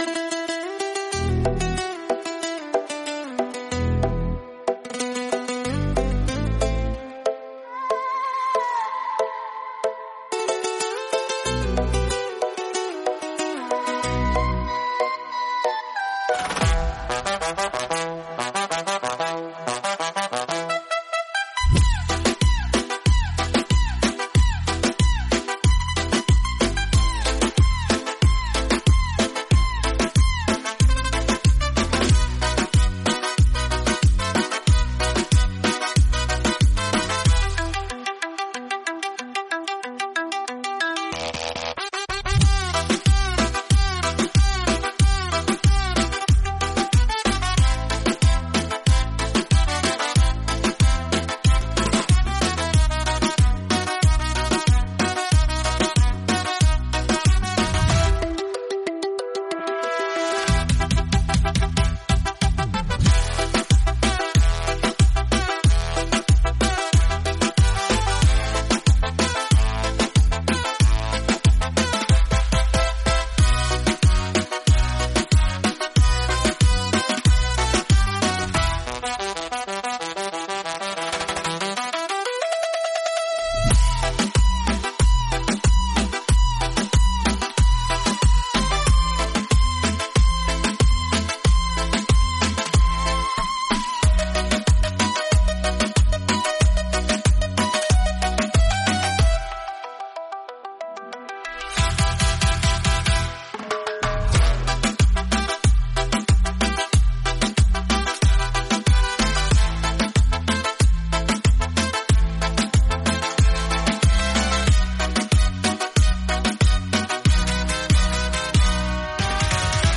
Beat Reggaeton Instrumental
Acapella e Cori Reggaeton Inclusi
• Mix e mastering di qualità studio
Em